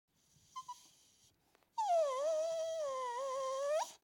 جلوه های صوتی
دانلود صدای سگ 13 از ساعد نیوز با لینک مستقیم و کیفیت بالا
برچسب: دانلود آهنگ های افکت صوتی انسان و موجودات زنده دانلود آلبوم صدای انواع سگ از افکت صوتی انسان و موجودات زنده